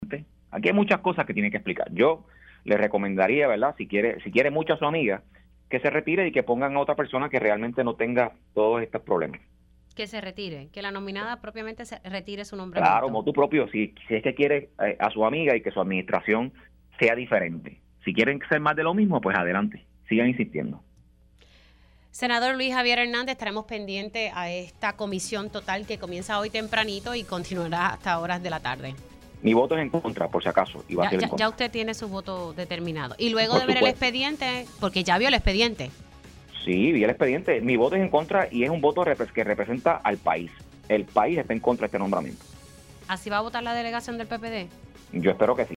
310-LUIS-JAVIER-HERNANDEZ-PORTAVOZ-PPD-SENADO-LE-VOTARA-EN-CONTRA-A-VERONICA-FERRAIOULI.mp3